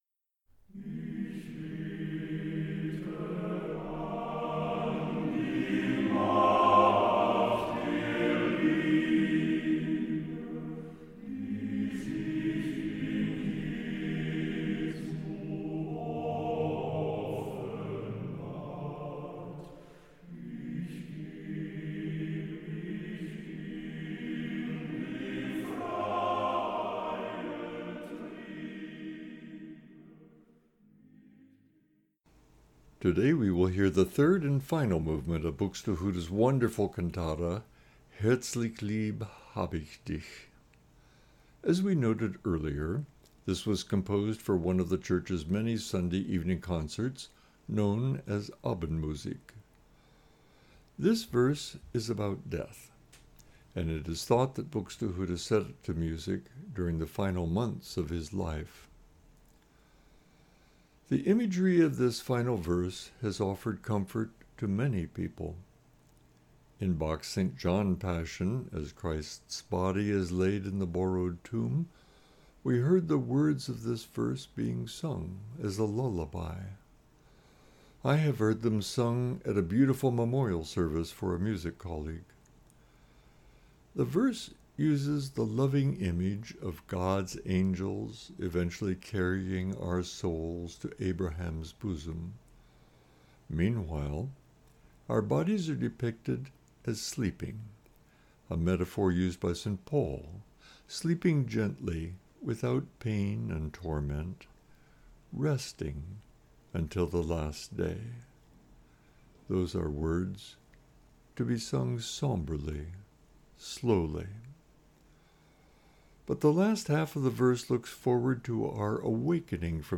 2025-03-09 Meditation for the First Sunday of Lent (Buxtehude - Herzlich lieb hab ich dich - Bux WV 41-3)